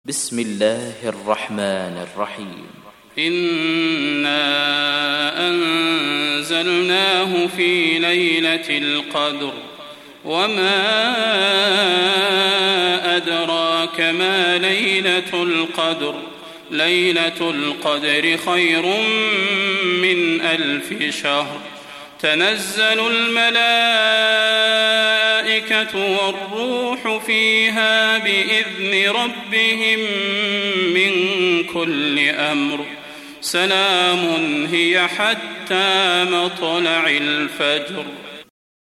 دانلود سوره القدر mp3 صلاح البدير روایت حفص از عاصم, قرآن را دانلود کنید و گوش کن mp3 ، لینک مستقیم کامل